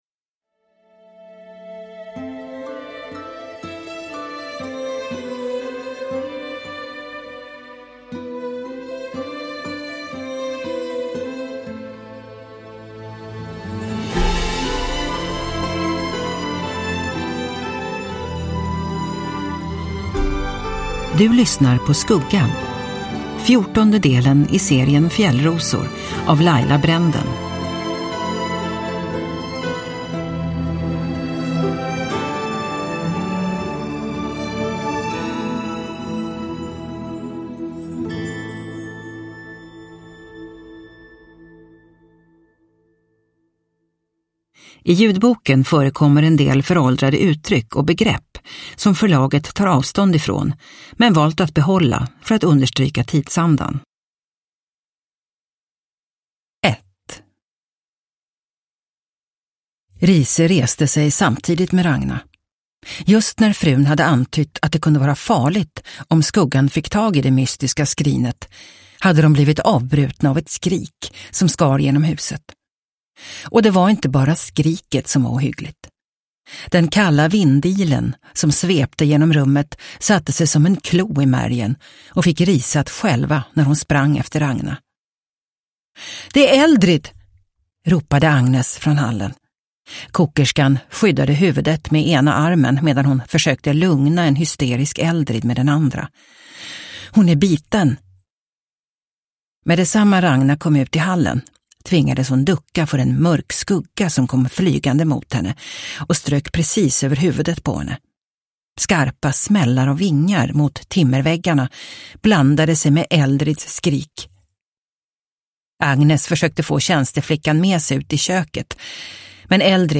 Skuggan – Ljudbok – Laddas ner